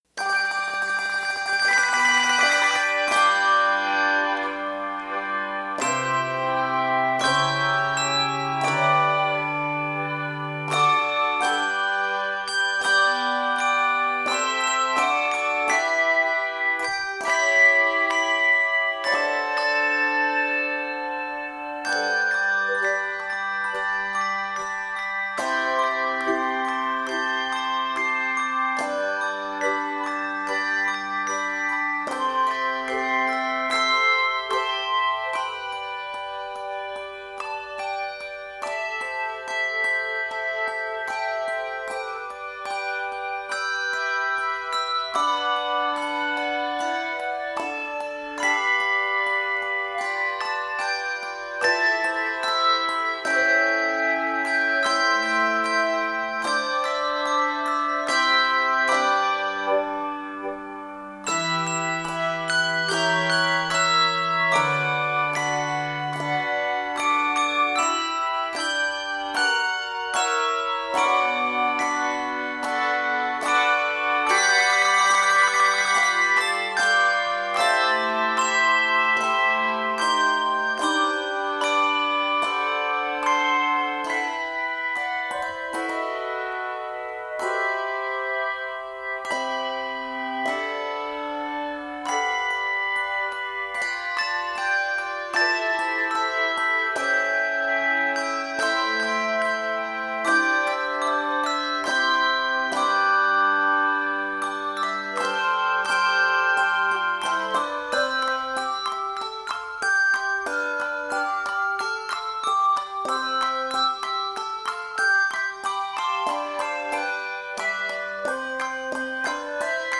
handbells
joyful (of course!) arrangement